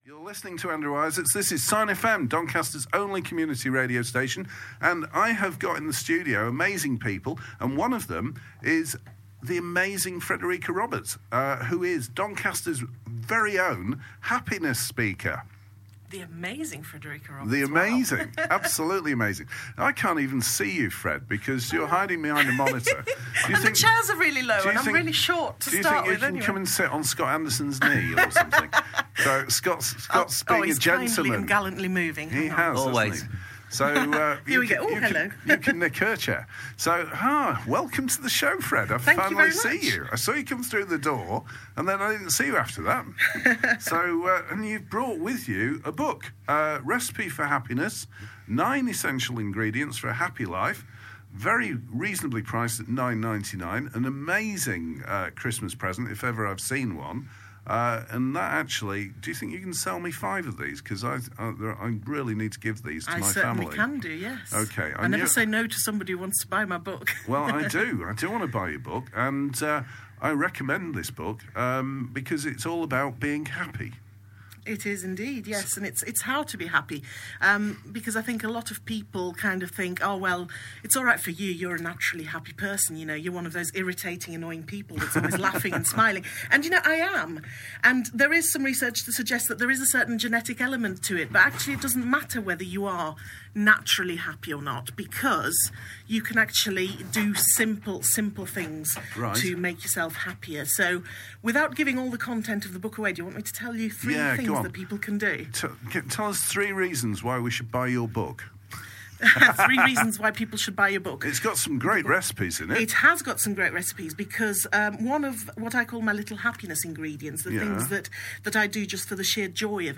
Three simple tips to become happier and lots of laughter in-between!